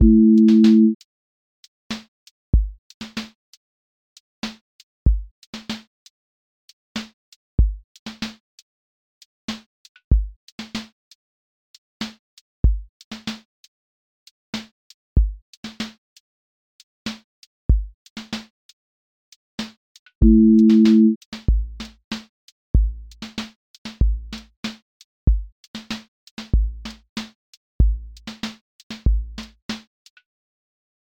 QA Listening Test r&b Template: rnb_pocket
r&b pocket with warm chord bed
• voice_kick_808
• voice_snare_boom_bap
• voice_hat_rimshot
• voice_sub_pulse